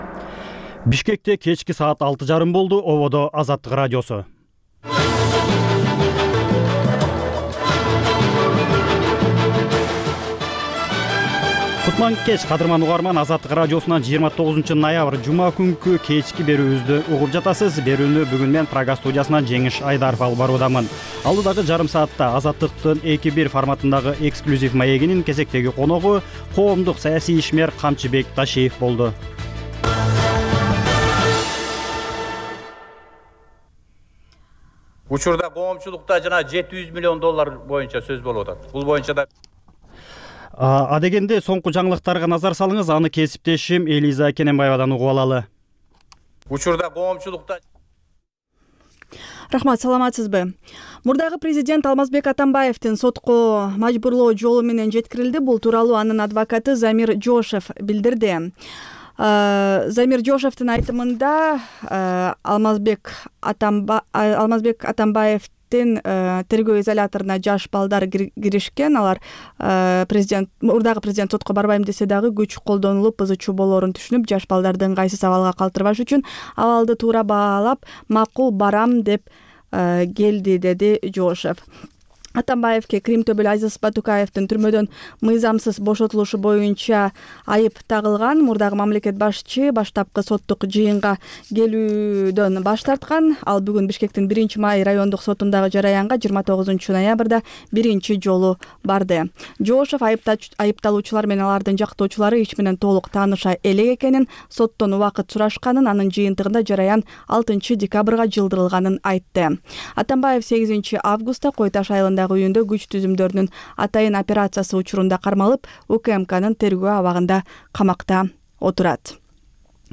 Бул үналгы берүү ар күнү Бишкек убакыты боюнча саат 18:30дан 19:00гө чейин обого түз чыгат.